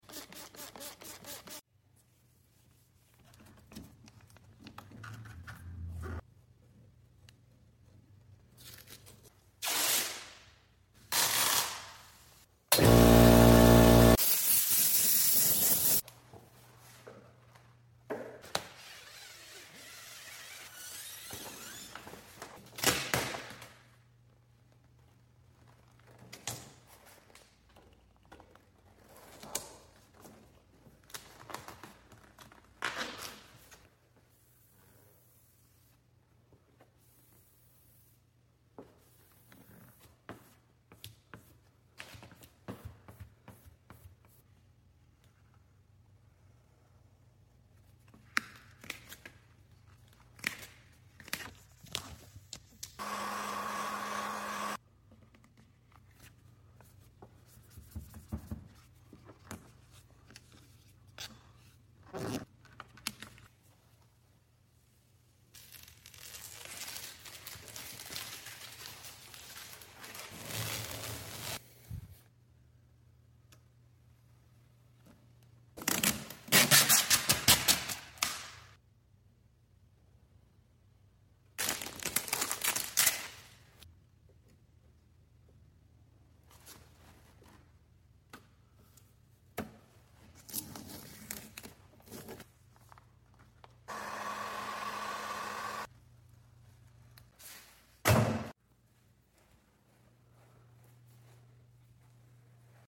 ASMR Vinyl Wrap Install Of sound effects free download
ASMR Vinyl Wrap Install Of Door On Mustang